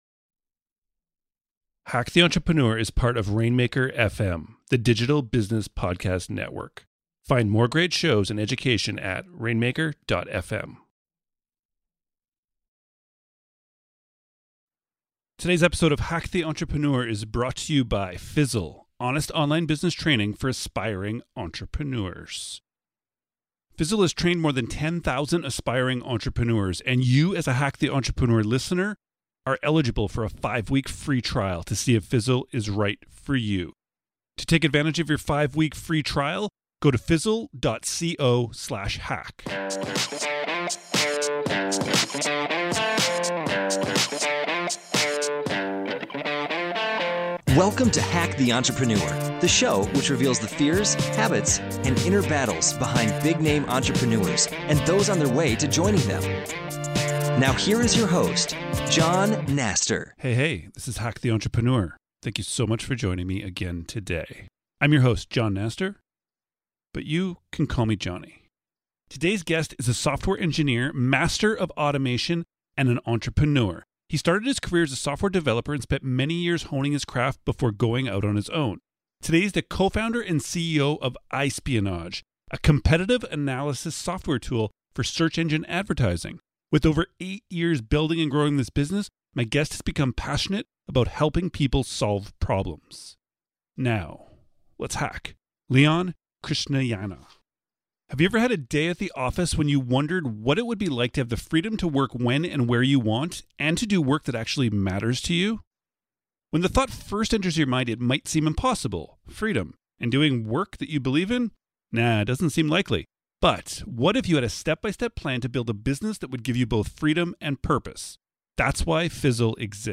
Today’s guest is a software engineer, master of automation, and an entrepreneur.